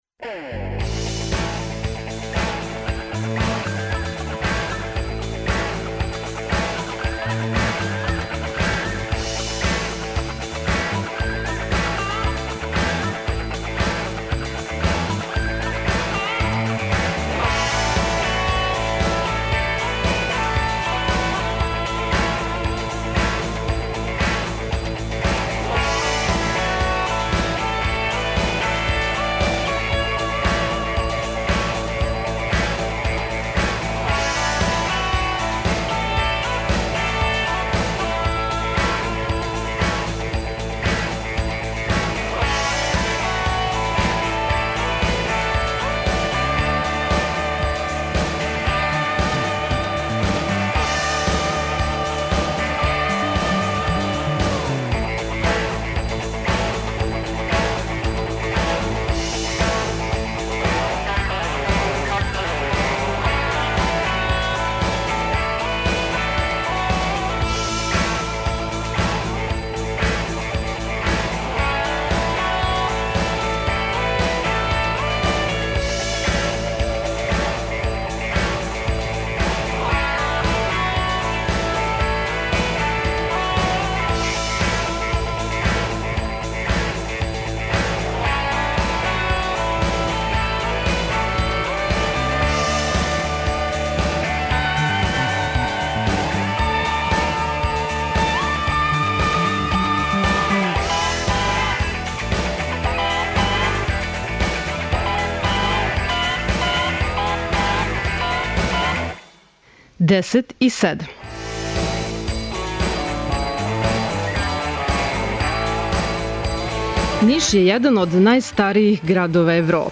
Магазин овог понедељка емитујемо из нашег студија у Нишу. Говорићемо о настанку и богатој историји овог града, о Медијани, Нишкој бањи, нишким научним и културним институцијама, фестивалима и манифестацијама, познатим Нишлијама, нишким изрекама и речнику, али и о надалеко чувеном нишком буреку.